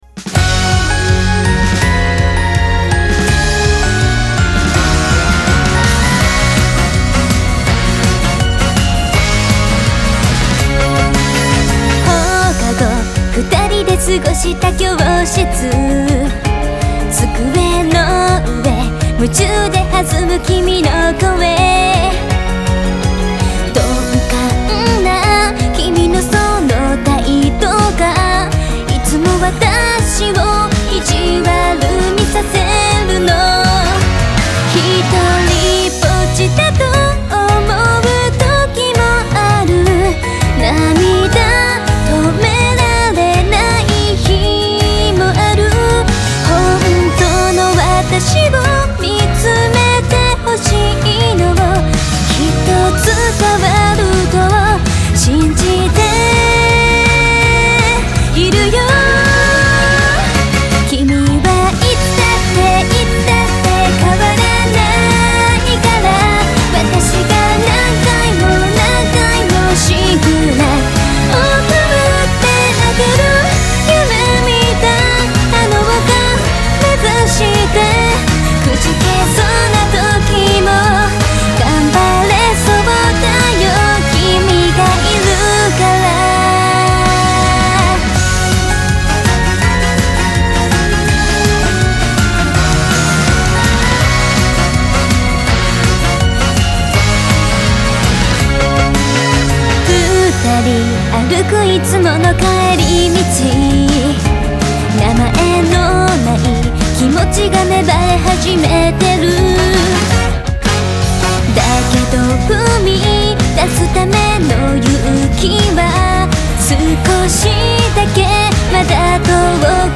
Country: Japan, Genre: J-Pop